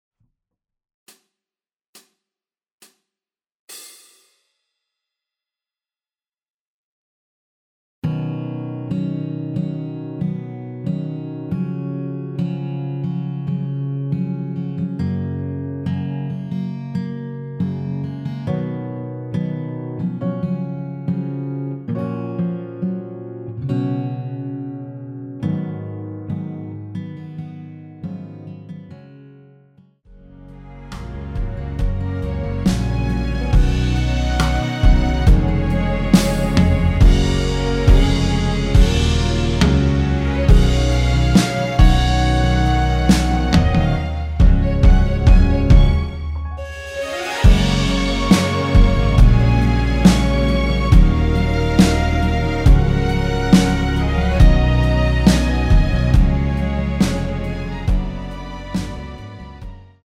전주 없이 시작하는 곡이라서 시작 카운트 만들어놓았습니다.(미리듣기 확인)
원키에서(-2)내린 MR입니다.
Gb
앞부분30초, 뒷부분30초씩 편집해서 올려 드리고 있습니다.
중간에 음이 끈어지고 다시 나오는 이유는